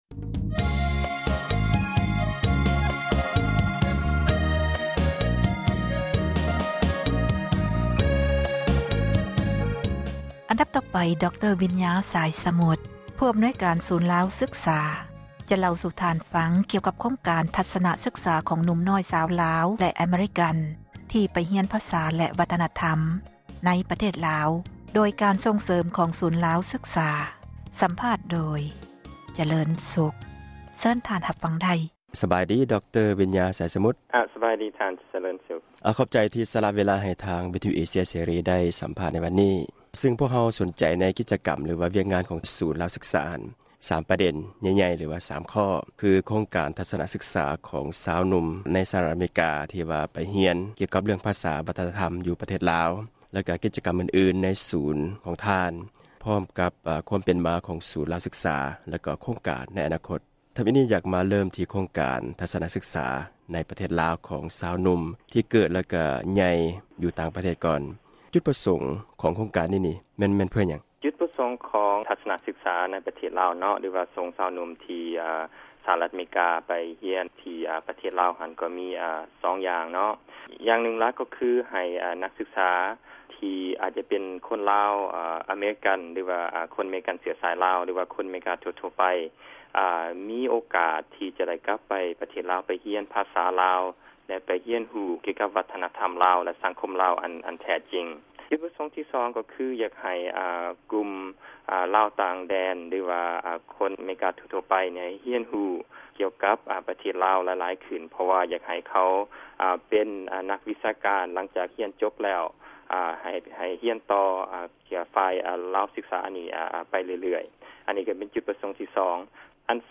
ສູນລາວສຶກສາ ໄດ້ນໍາພາ ນັກສຶກສາ ໄປຮຽນພາສາ ແລະ ວັທນະທັມລາວ ທີ່ປະເທດລາວ ເປັນເວລາ 8 ສັປດາ ເພື່ອໃຫ້ນັກຮຽນດັ່ງກ່າວ ໄດ້ກັບຄືນ ຖິ່ນລໍາເນົາ ຂອງພໍ່ແມ່ ຂະເຈົ້າ ແລະ ຮຽນຮູ້ ວິຖີຊີວິດ ໃນເຂດຊົນນະບົດ. ເຊີນຟັງຣາຍລະອຽດ ຈາກການສັມພາດ